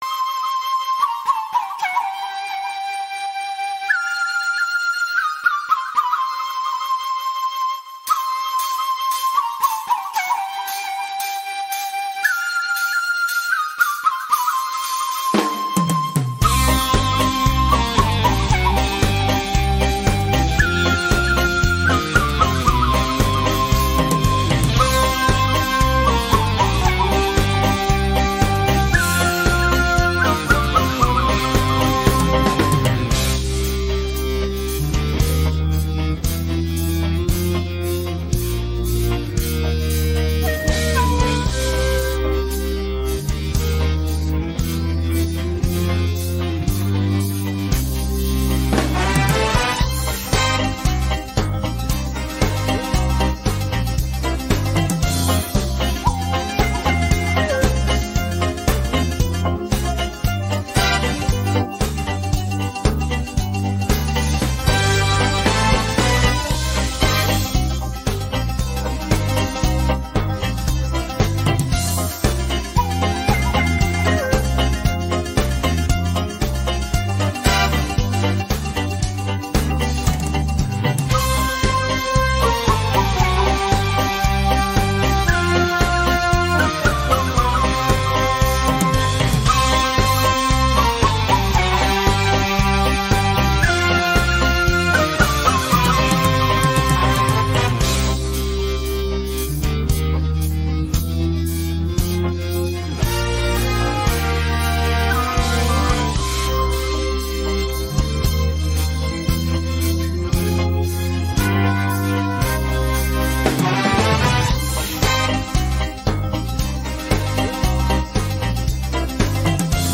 pop караоке